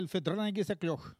Mémoires et Patrimoines vivants - RaddO est une base de données d'archives iconographiques et sonores.
Il fait sonner sa cloche